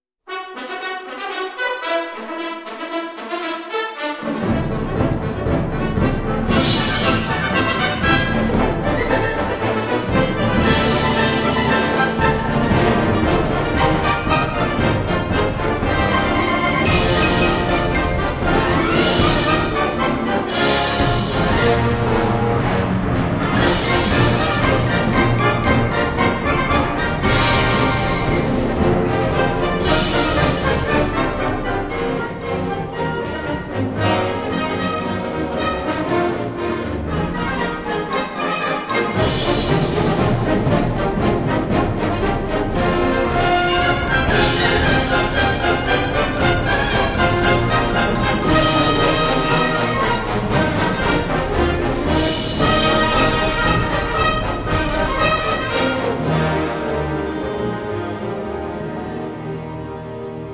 · Newly Recorded in Stunning and Dynamic Digital Sound
130-piece